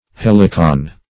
Helicon \Hel"i*con\ (h[e^]l"[i^]*k[o^]n), prop. n. [L., fr. Gr.